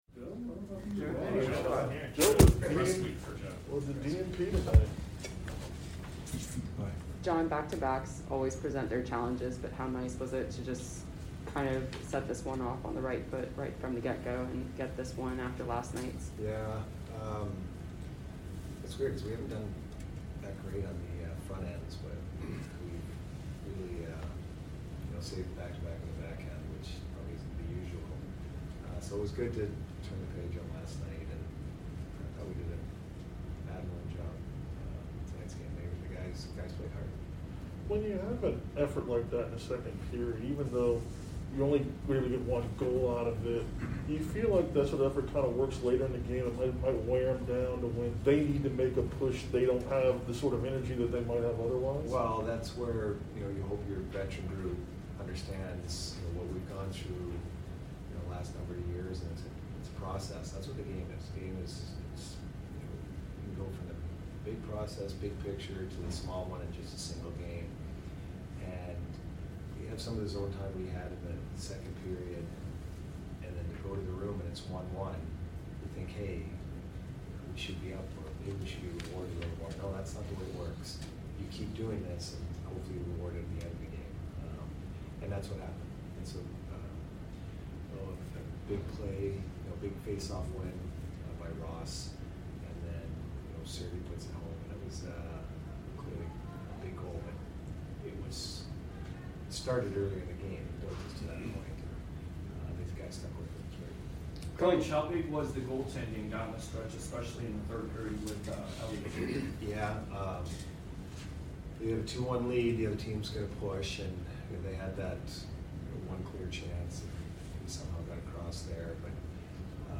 Head Coach Jon Cooper Post Game Vs DET 3 - 4-2022